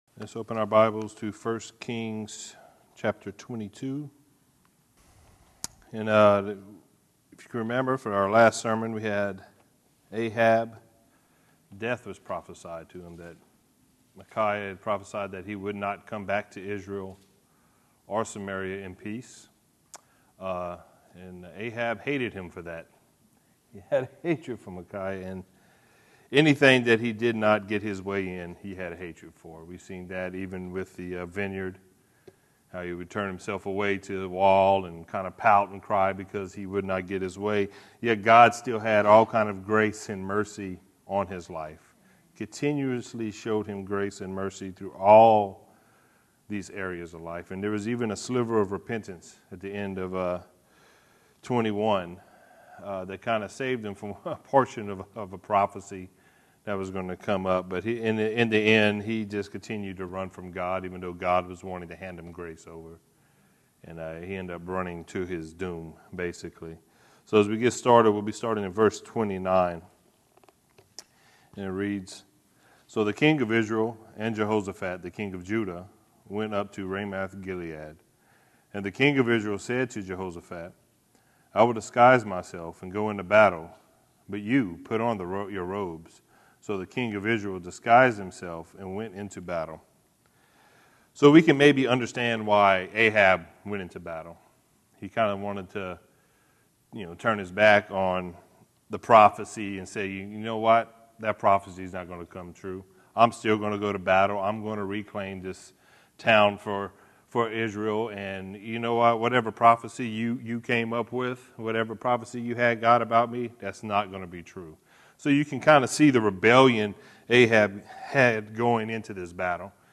The book of 1 Kings is the sequel to 1 and 2 Samuel and begins by tracing Solomon’s rise to kingship after the death of David. The story begins with a united kingdom, but ends in a nation divided into 2 kingdoms. Join us for this verse by verse study